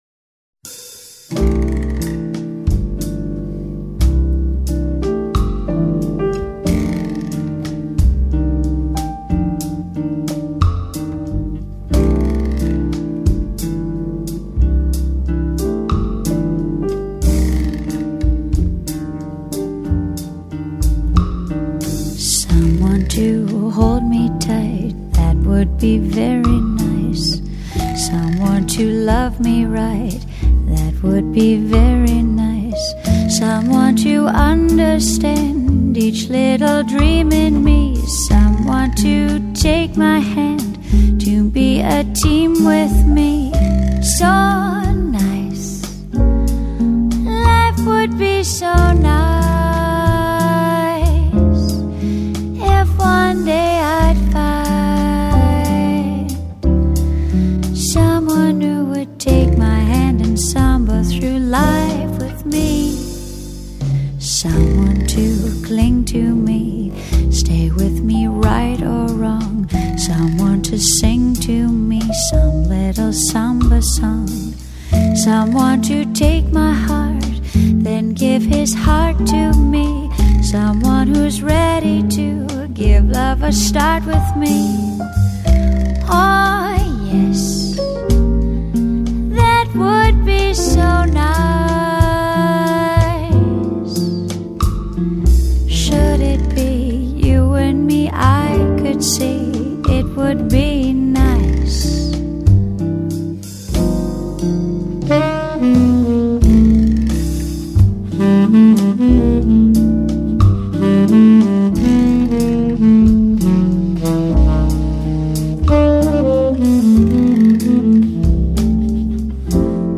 音樂類型：爵士樂